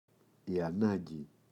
ανάγκη, η [a’naŋgi]